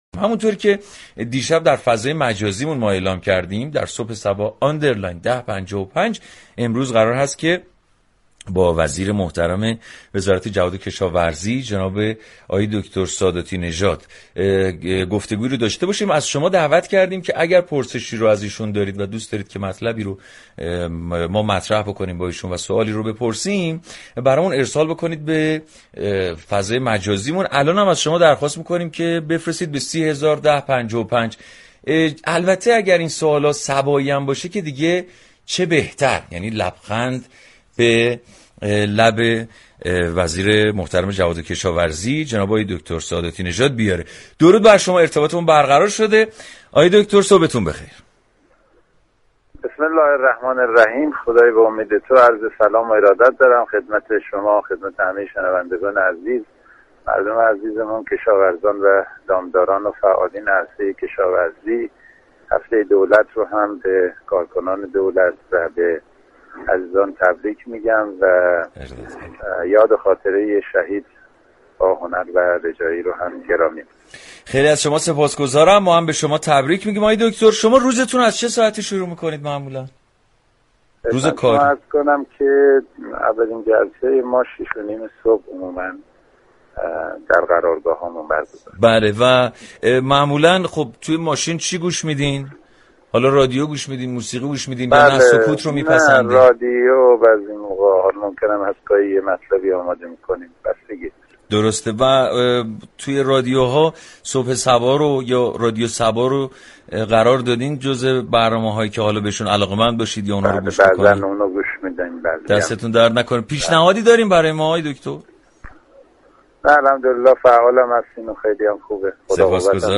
وزیر كشاورزی در گفتگو با «رادیو صبا» از دستاوردهای دولت سیزدهم گفت و مسولین را به برای شنیدن صدای مردم از «صبح صبا» دعوت كرد
به گزارش روابط عمومی رادیو صبا، برنامه زنده «صبح صبا» هر روز در قالب مجله رادیویی صبحگاهی با هدف اطلاع رسانی آخرین اخبار روز را با نگاهی طنز به اطلاع مخاطبان می‌رساند.